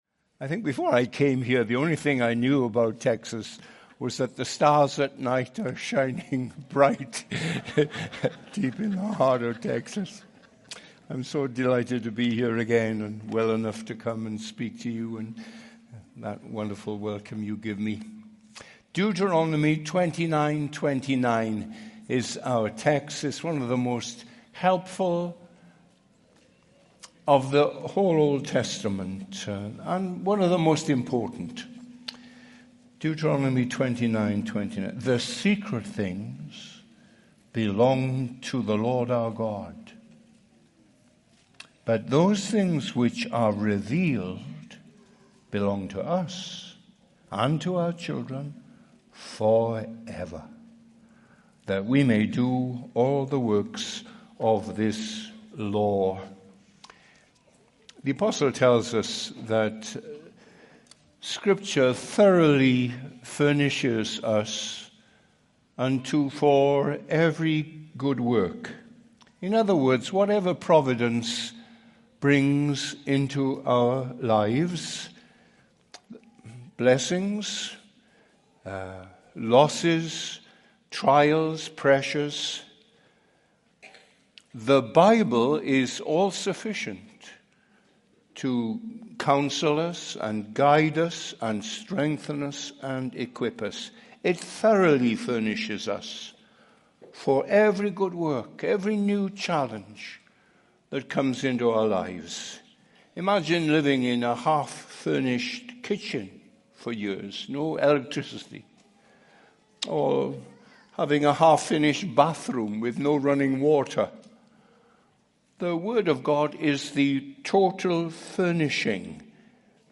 2022 Category: Full Sermons Are there mysteries that God has kept a secret?